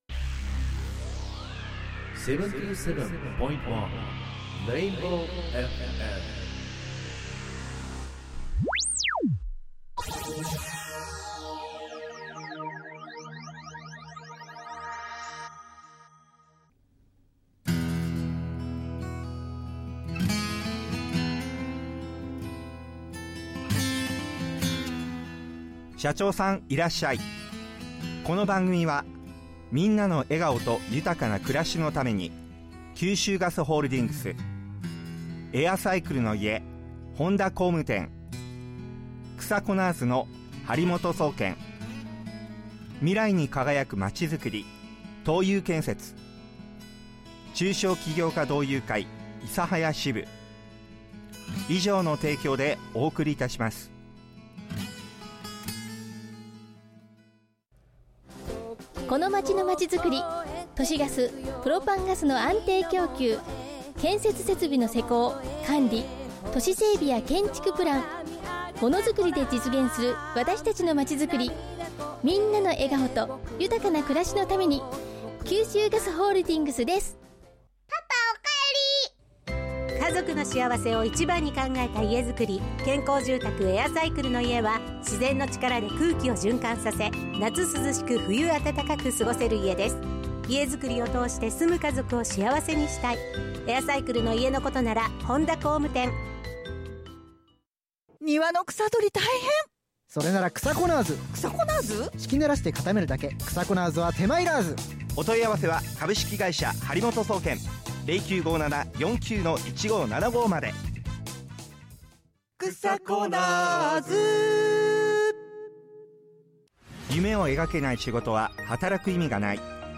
生放送を聴き逃した方はこちらより！